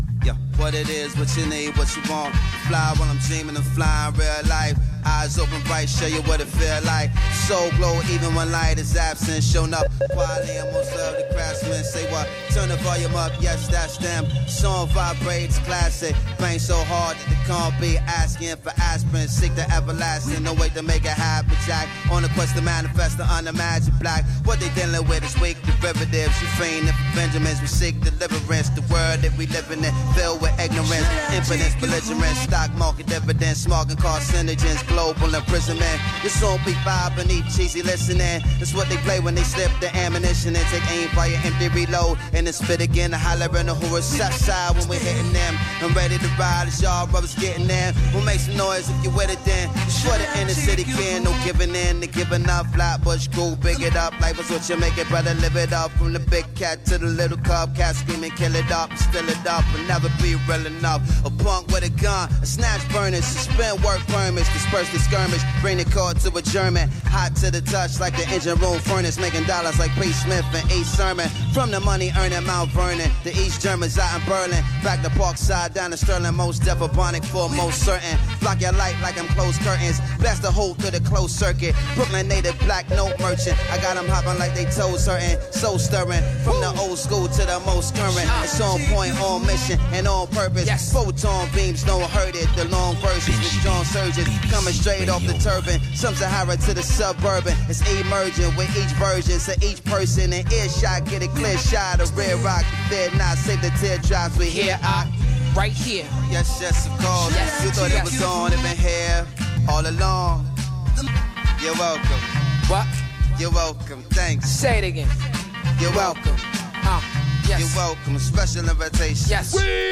This radio snippet possibly hints at what might be in store.